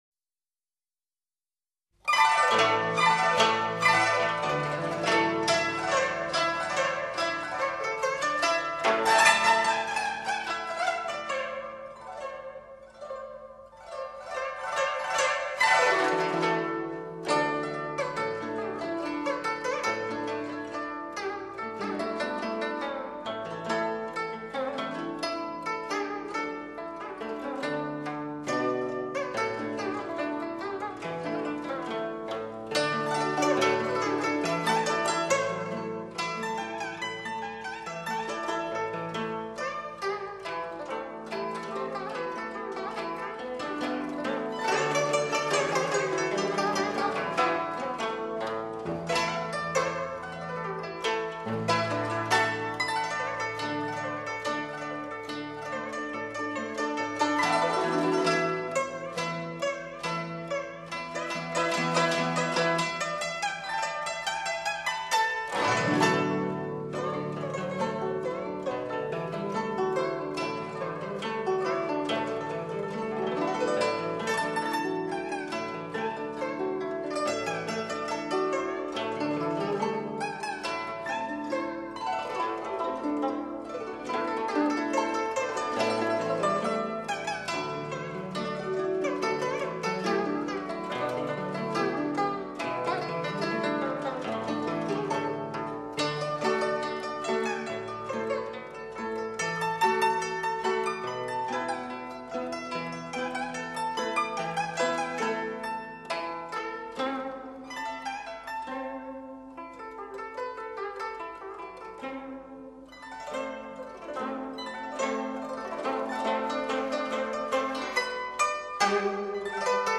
渔舟唱晚 古筝